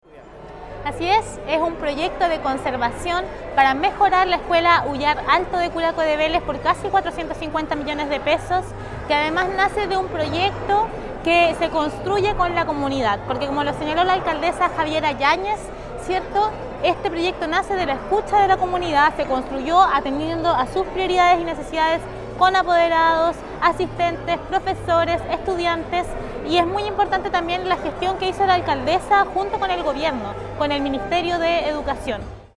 Por otra parte, la Seremi de Educación, Daniela Carvacho destacó: